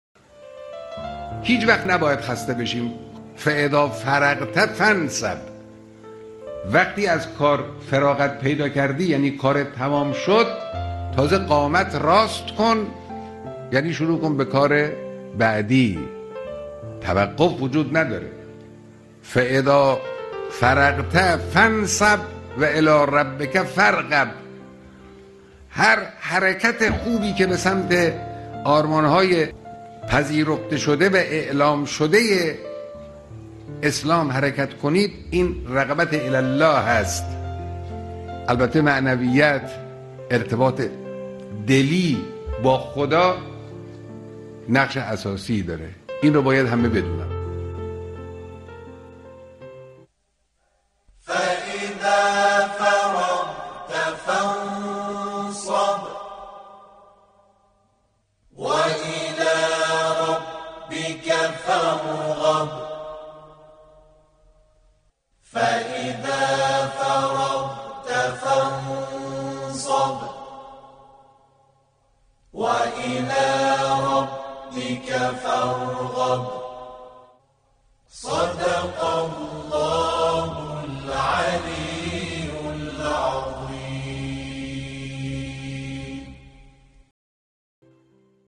صوت بیانات مقام معظم رهبری